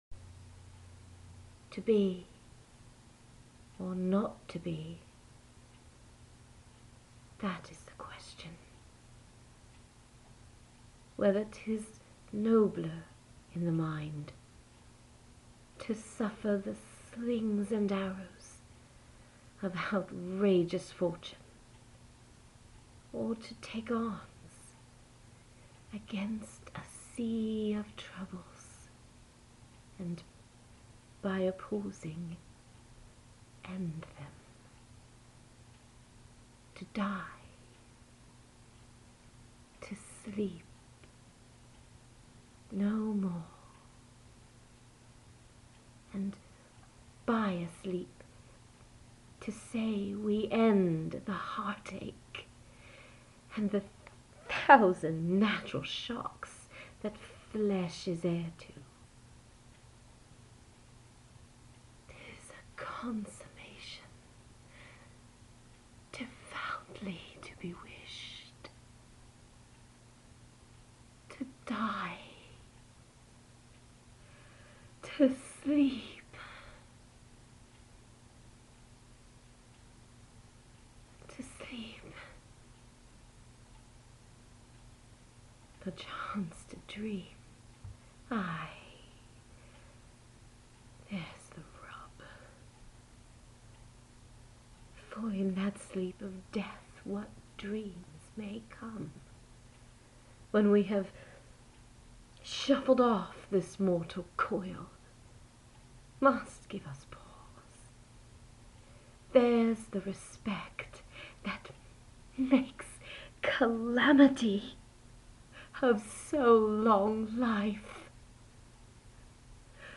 Thespian Exploits – My thoughts about Acting, as well as a few poems READ by me.